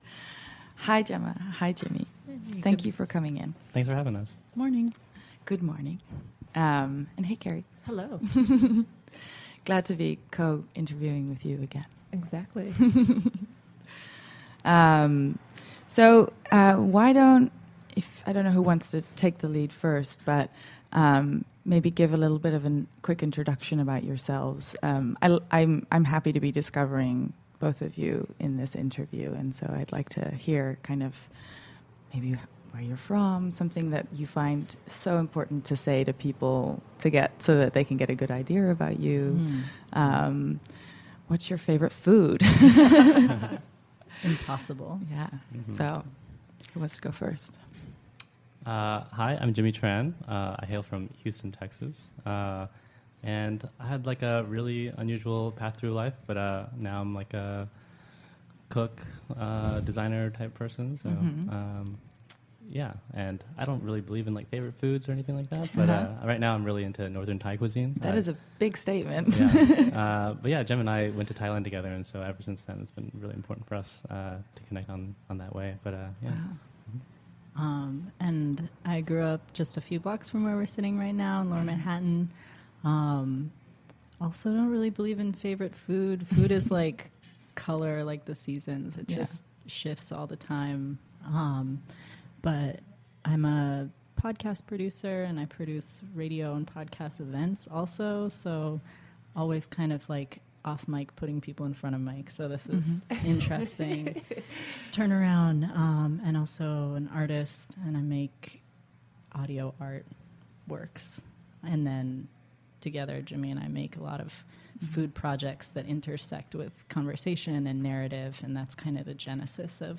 For season one, Office Hours , we took over a storefront in Chinatown and interviewed over 50 artists, designers, chefs, architects, entrepreneurs, and one politician, all to find out how they managed to make money doing what they love.
All of the interviews were recorded live.